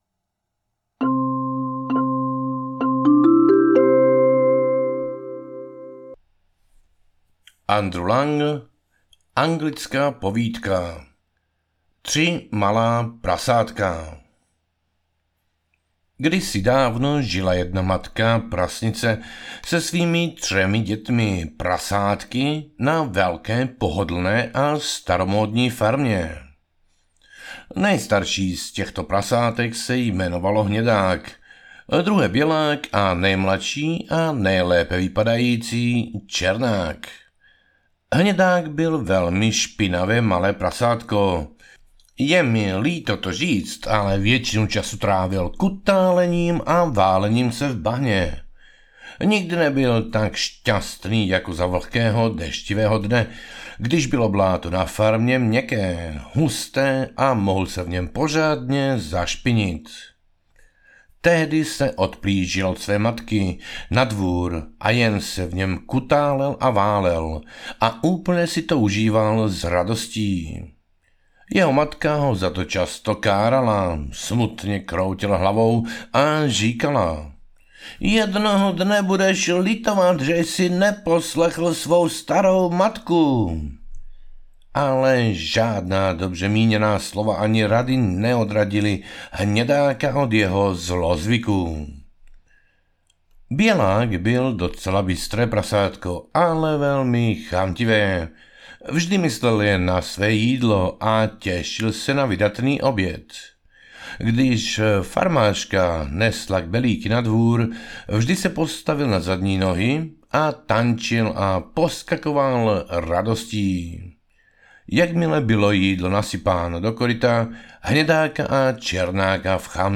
Ukázka z knihy
povidky-a-vety-1-ceske-vydani-audiokniha